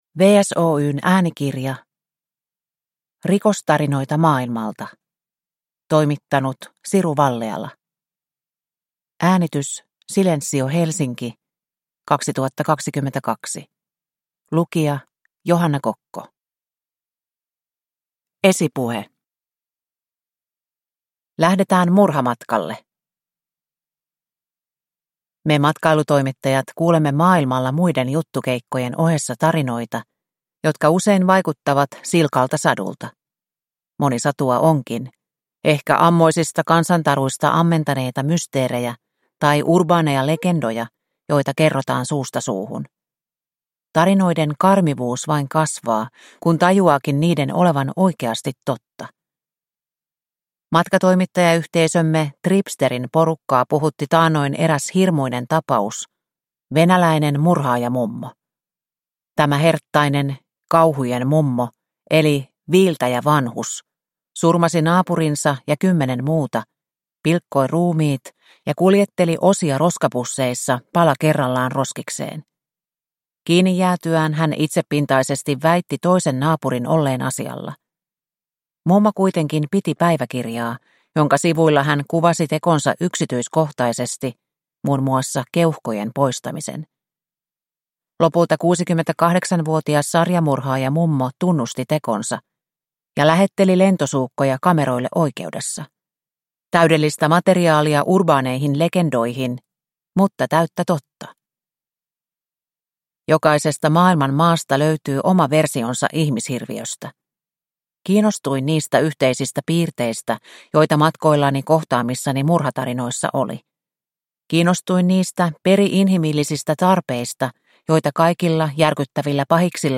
Rikostarinoita maailmalta – Ljudbok